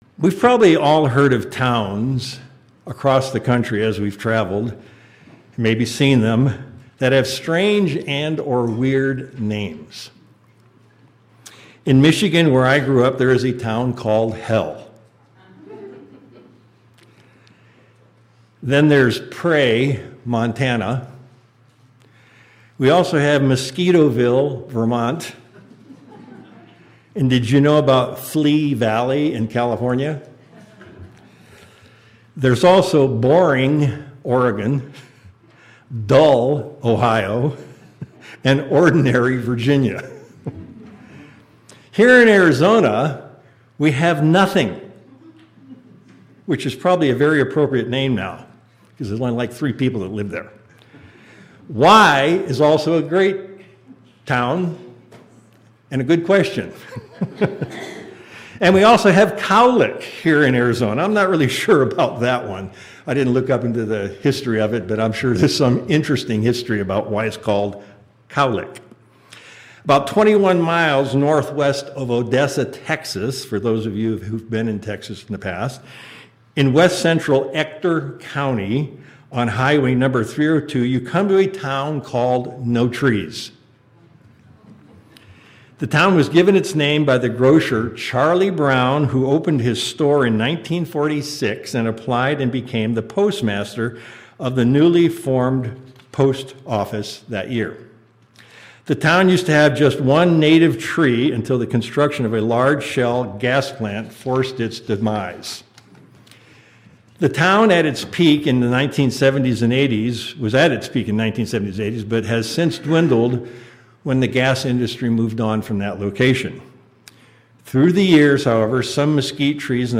We all have probably read about Adam, Zacchaeus and Elijah in the Bible. There is a relationship between them that we can all learn from, which is what we will do in today's sermon.
Sermons